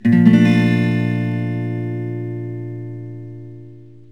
Asmaj7.mp3